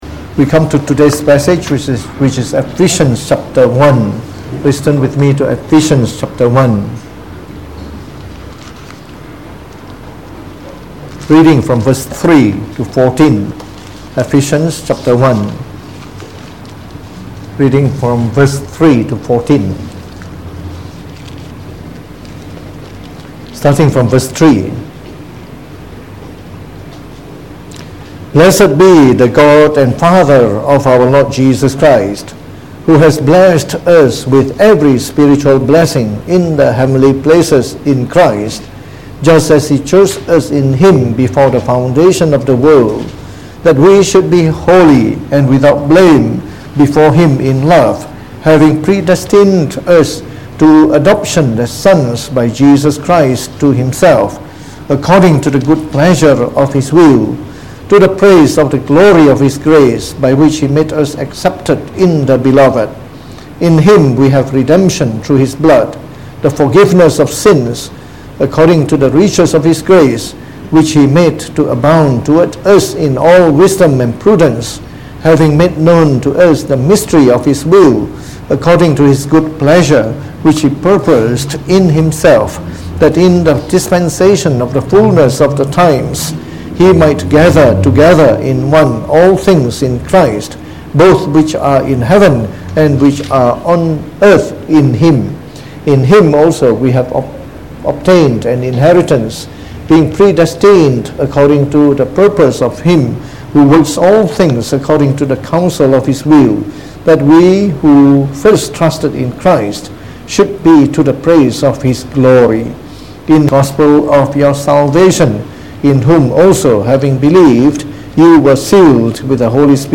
MP3 Sermons – 2020